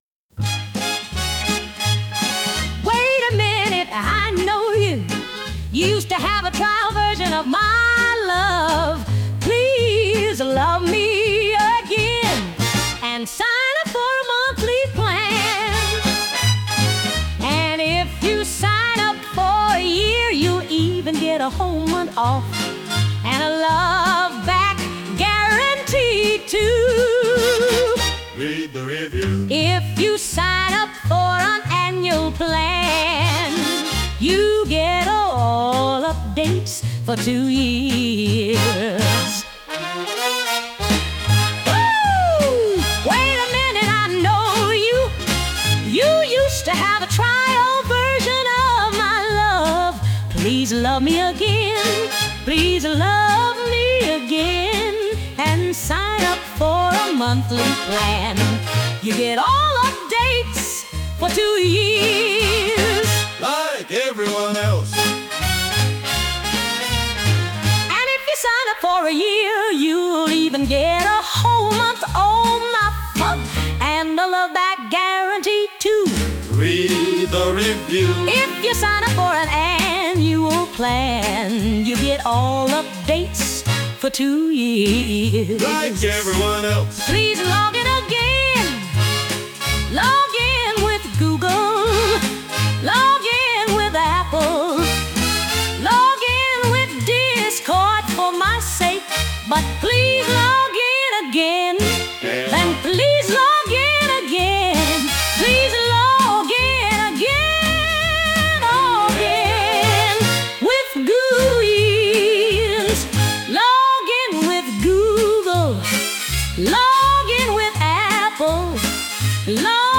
With Vocals / 歌あり
タイトルそのままの、王道で魅力的な「女性ボーカルのレトロジャズ」！
リズムが非常に取りやすく踊りやすいテンポに仕上がっているため、振付の構成もスムーズに進みます。